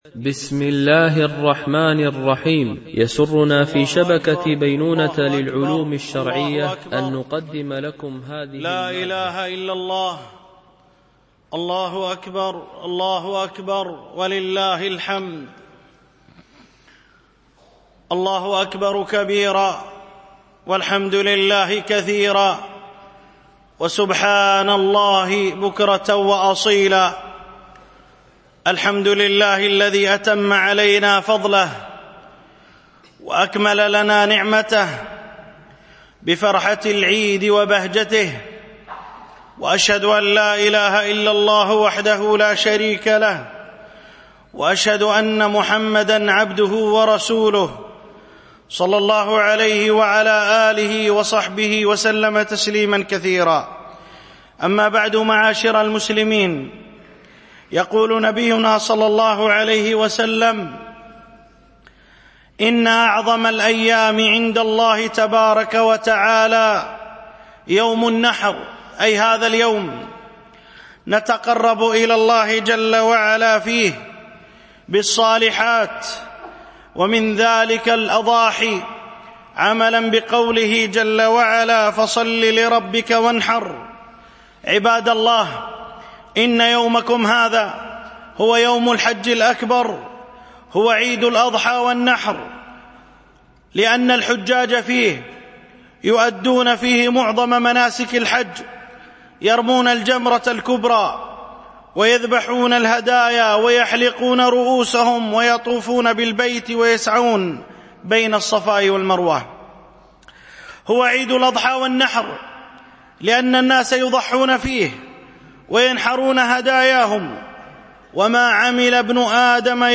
خطبة عيد الأضحى 1439 هـ